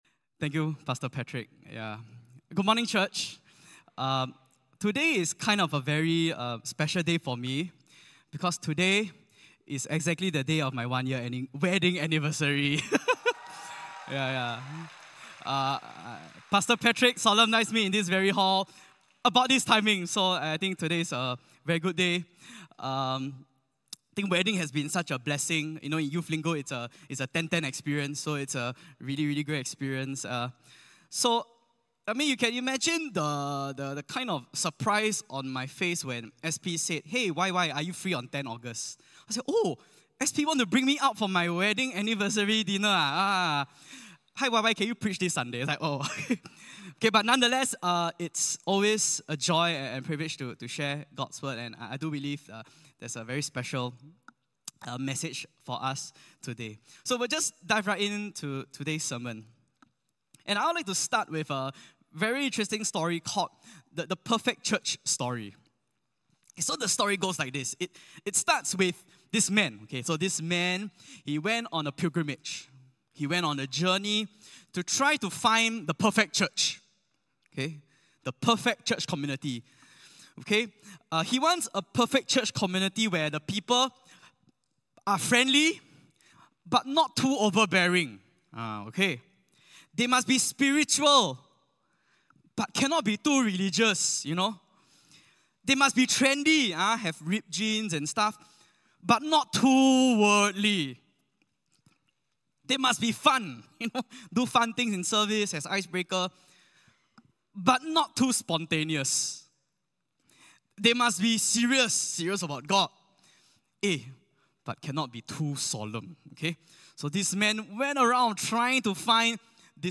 Sermon Audio (.mp3)